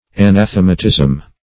Search Result for " anathematism" : The Collaborative International Dictionary of English v.0.48: Anathematism \A*nath"e*ma*tism\, n. [Gr.